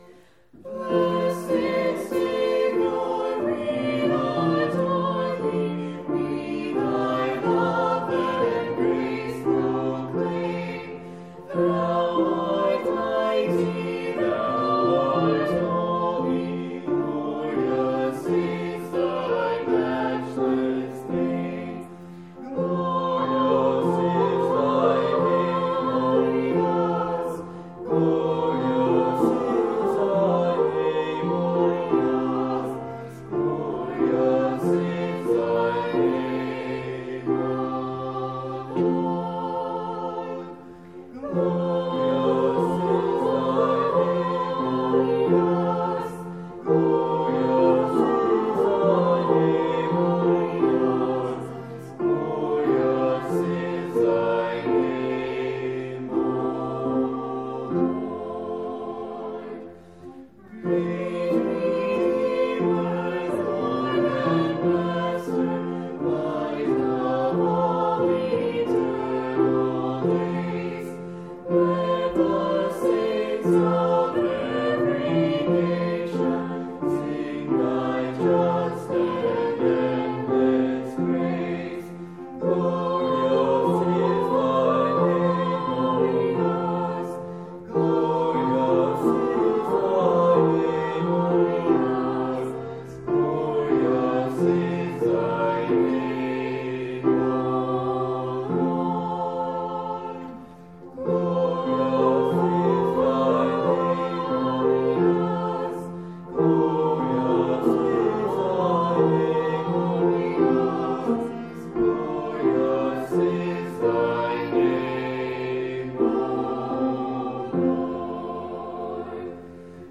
5.10.20-Hymns.mp3